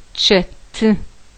чэты chat